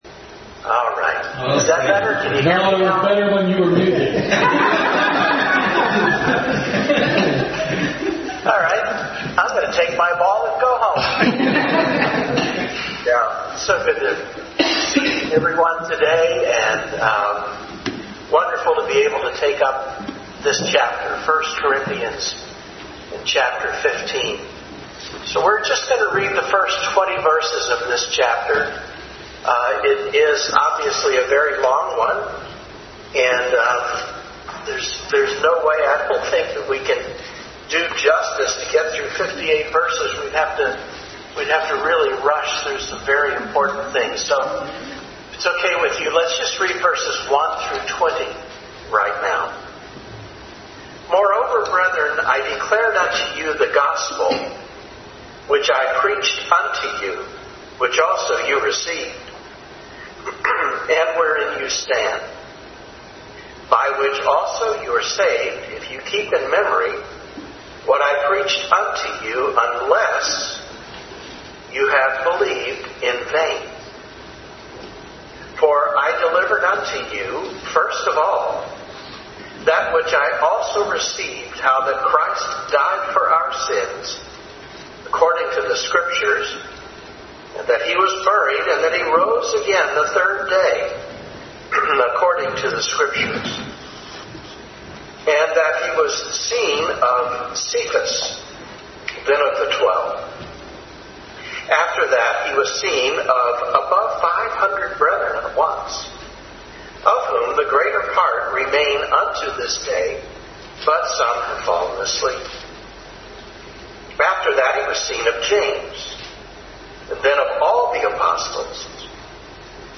Adult Sunday School Class continued study in 1 Corinthians.
1 Corinthians 15:1-20 Service Type: Sunday School Adult Sunday School Class continued study in 1 Corinthians.